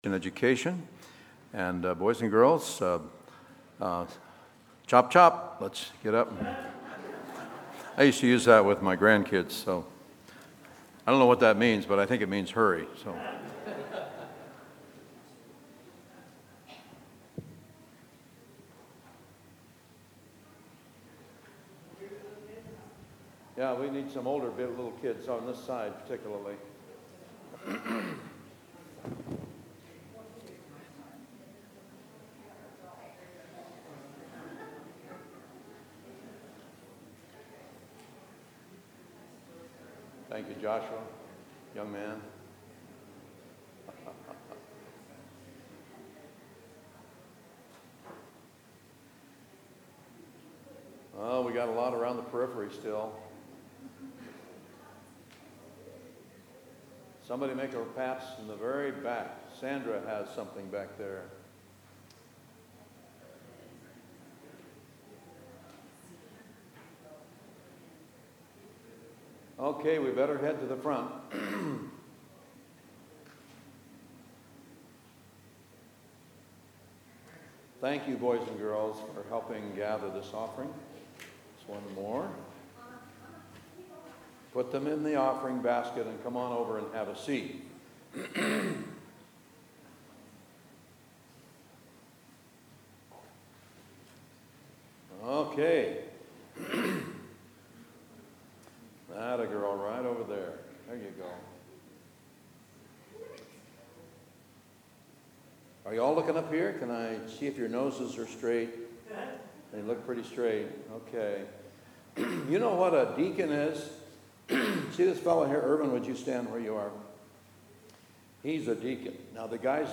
Past Sermons